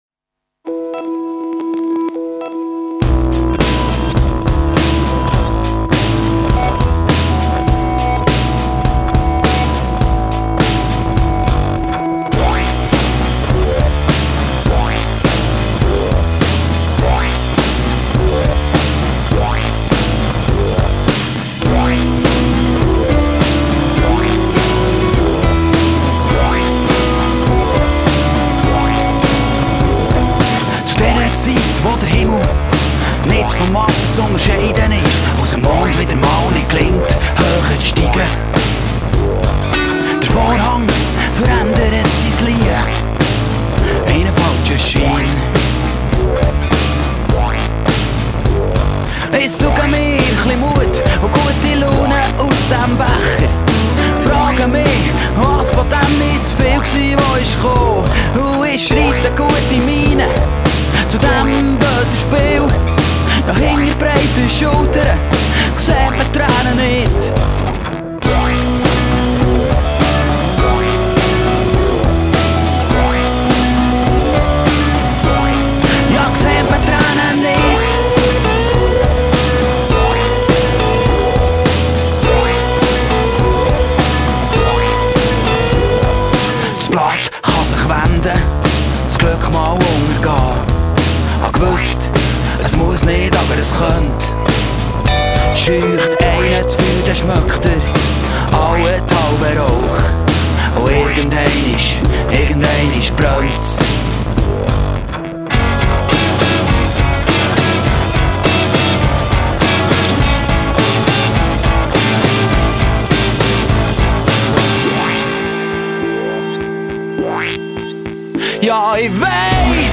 Swiss-German dialect rock.